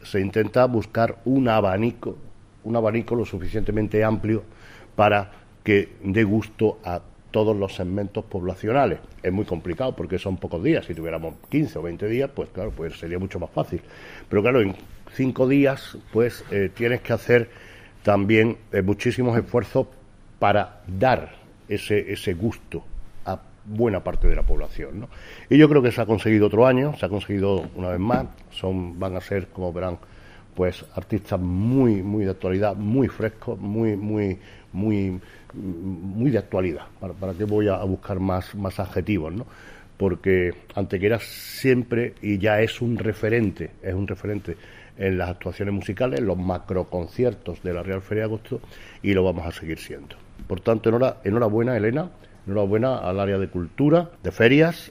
El alcalde de Antequera, Manolo Barón, y la teniente de alcalde de Cultura, Ferias, Tradiciones
Cortes de voz